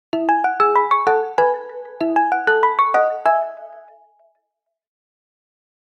Category Alarm